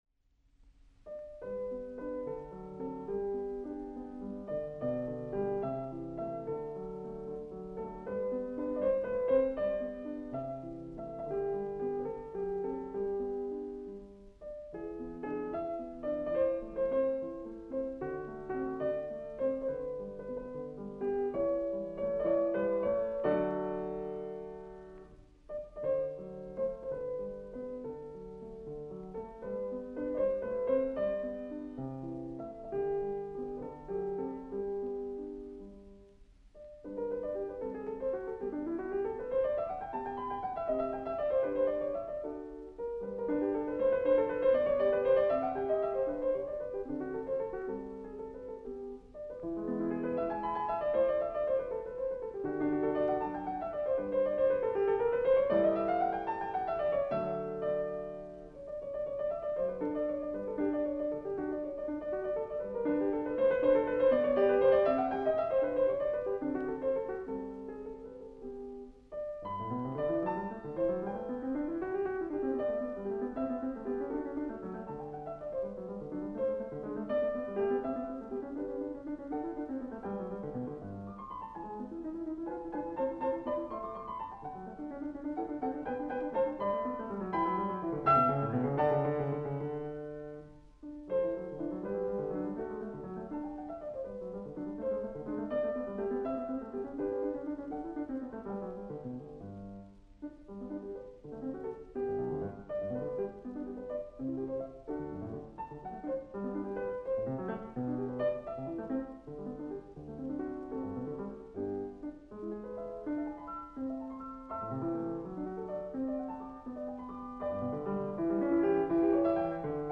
The work is in G major, and the variations remain in that key except for Variation 4, which ventures into G minor.
This recording was made in 1960 by German pianist Dieter Zechlin, who, at the time, was one of East Germany’s leading pianists.
Performed by